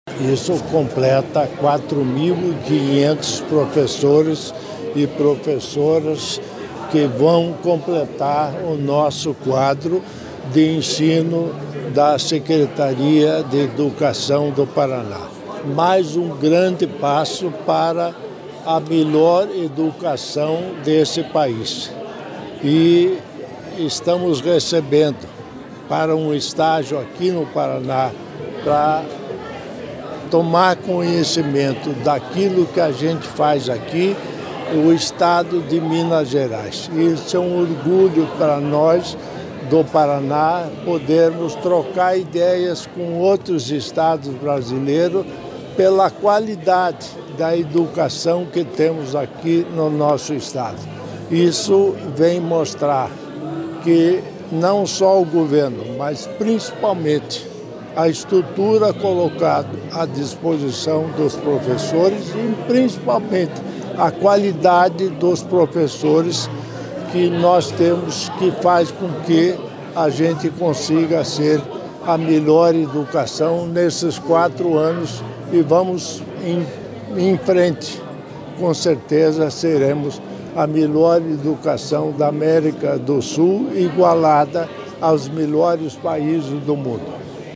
Sonora do governador em exercício Darci Piana sobre a nomeação de mais 988 professores da rede estadual